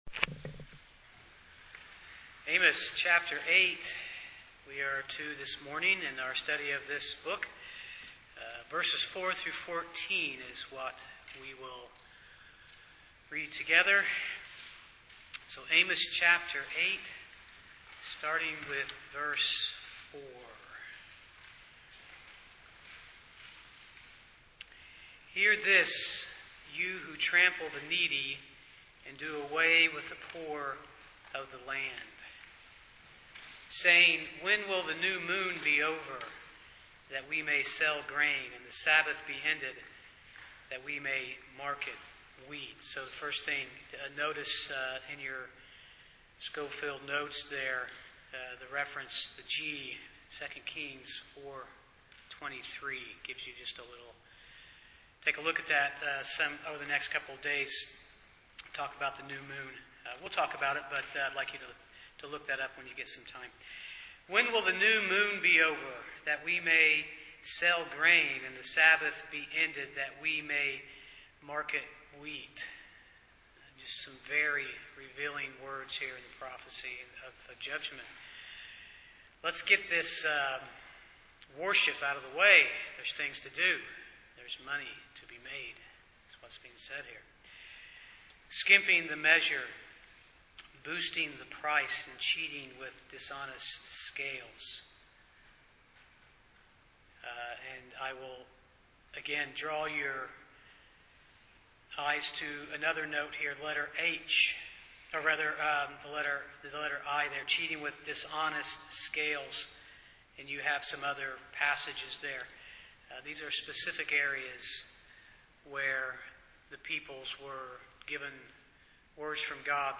Amos 8:4-14 Service Type: Sunday morning Part 8 of the Sermon Series Topics